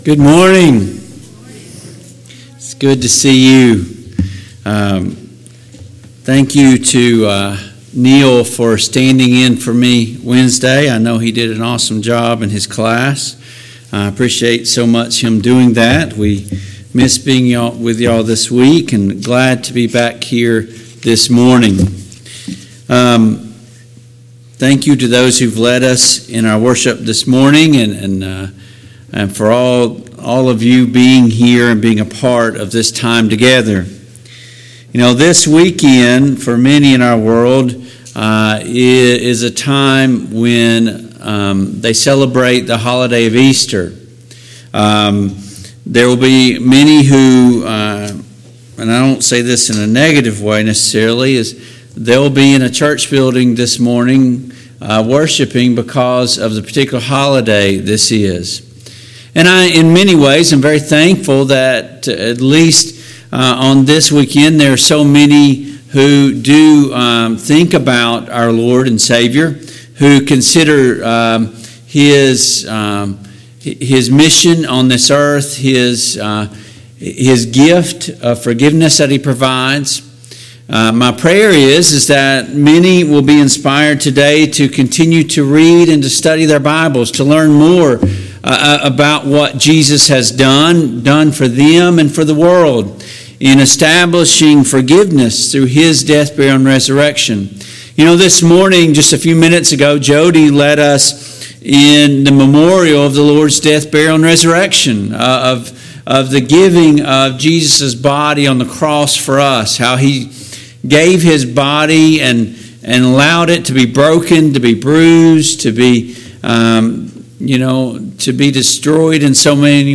Service Type: AM Worship Topics: The Death Burial & Resurrection of Jesus Christ , The Gospel of Jesus Christ